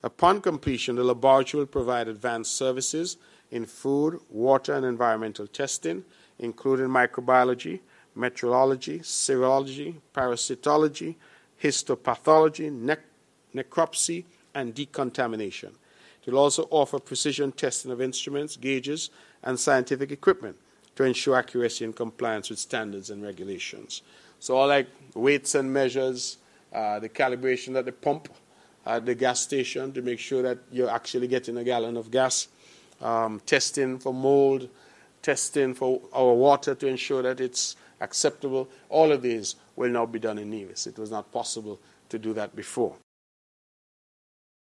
Premier of Nevis, the Hon. Mark Brantley gave information about its expected impact: